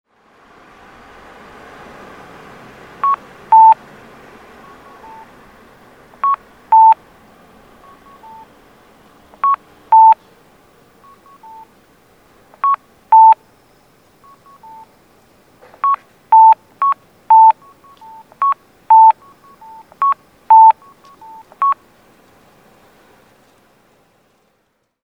南部出張所(大分県別府市)の音響信号を紹介しています。